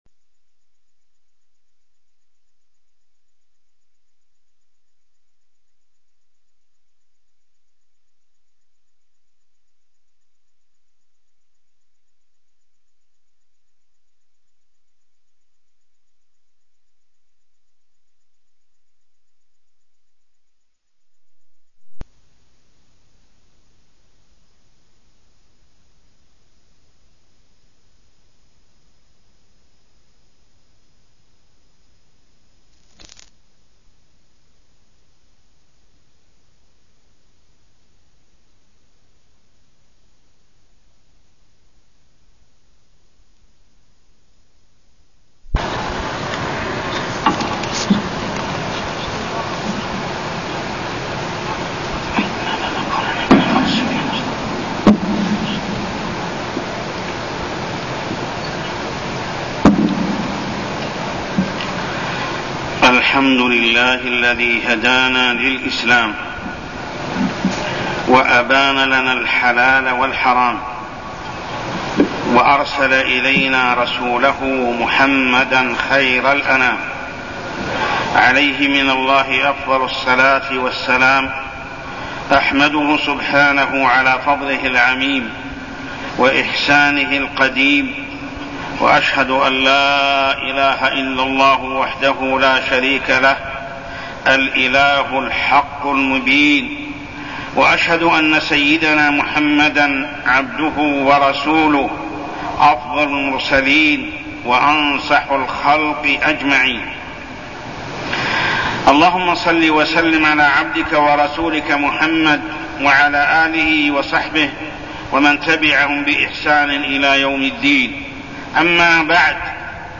تاريخ النشر ٢٩ ربيع الثاني ١٤١٤ هـ المكان: المسجد الحرام الشيخ: محمد بن عبد الله السبيل محمد بن عبد الله السبيل أصول الدين